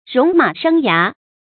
戎马生涯 róng mǎ shēng yá
戎马生涯发音
成语注音 ㄖㄨㄙˊ ㄇㄚˇ ㄕㄥ ㄧㄚˊ